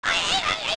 OOT_Twinrova_Argue3.wav